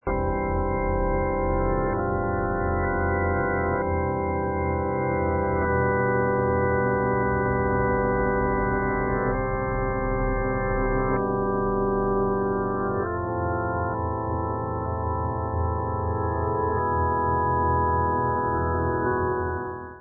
sledovat novinky v oddělení Rock/Progressive